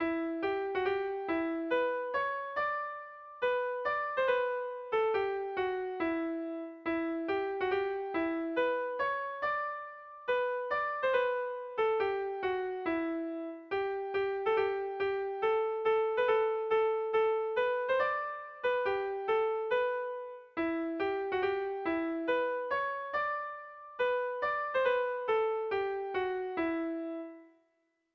Erlijiozkoa
AABA